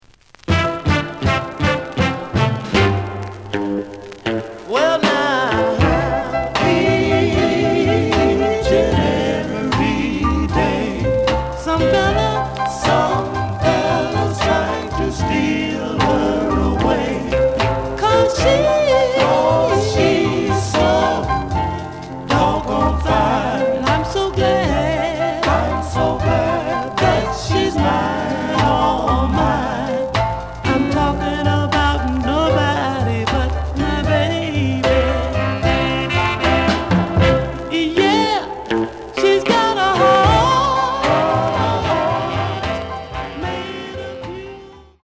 The audio sample is recorded from the actual item.
●Genre: Soul, 60's Soul
Slight affect sound.)